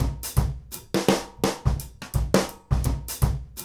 GROOVE 210KR.wav